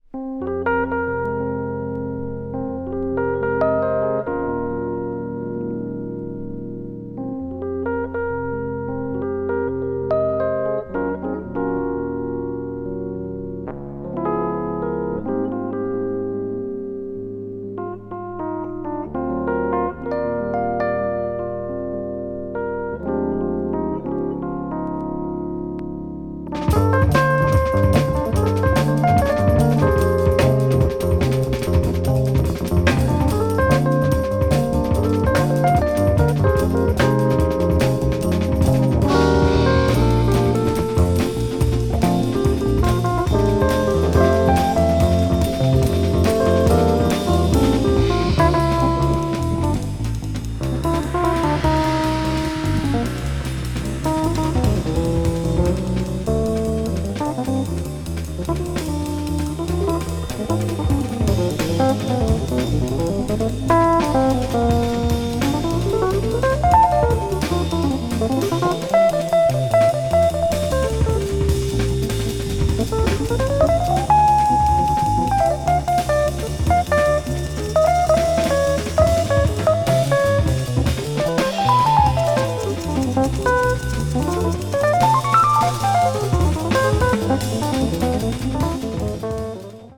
acoustic piano
electric piano
contemporary jazz   crossover   fusion   spritual jazz